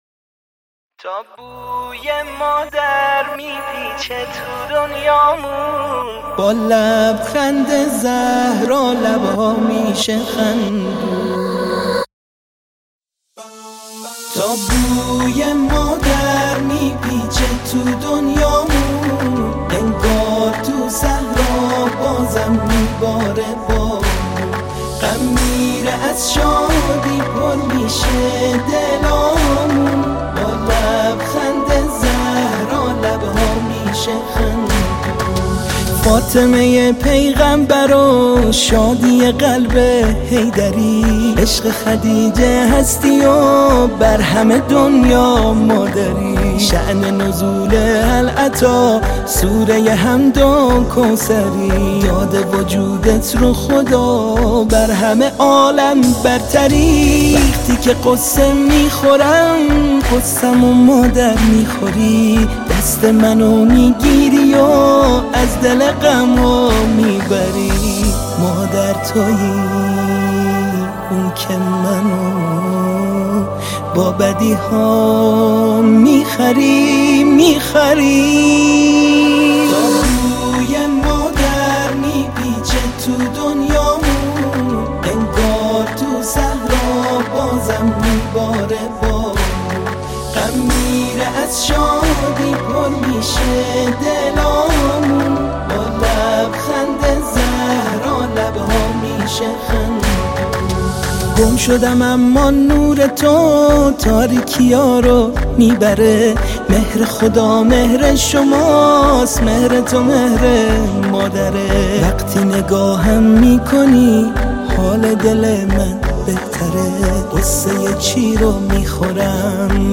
سرود روح‌بخش